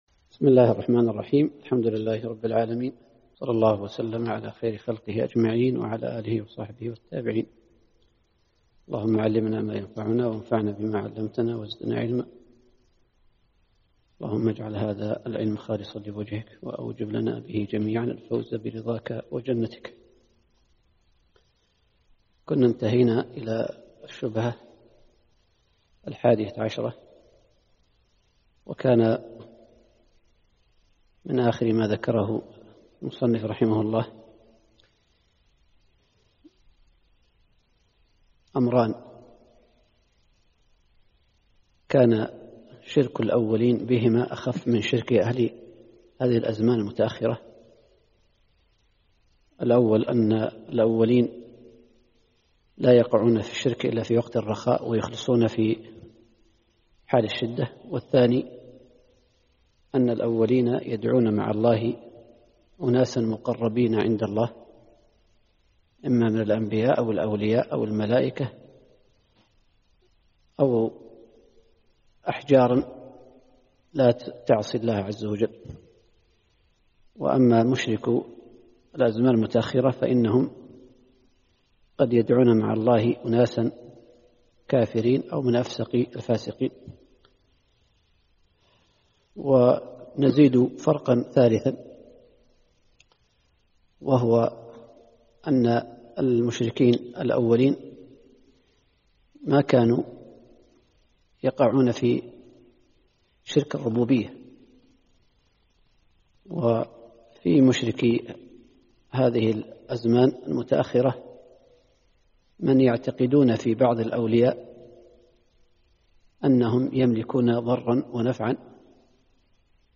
الدرس الثالث.